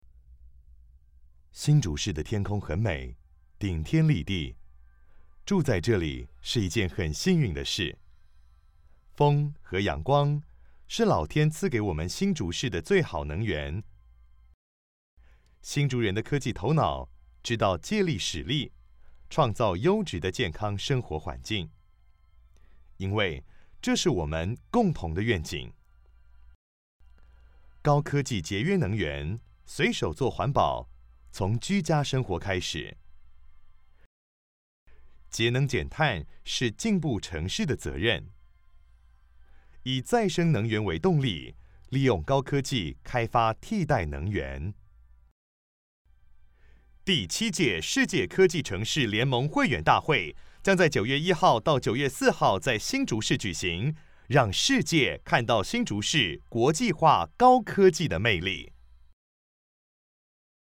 國語配音 男性配音員
無論是充滿激情的促銷廣告，還是柔和動人的情感訴求，他都能以細膩的聲音變化切換氛圍，將廣告訊息完美呈現。
他以字正腔圓、語速與情緒控制得當著稱，確保廣告文案的每個字都傳遞清晰，並且與品牌定位高度契合。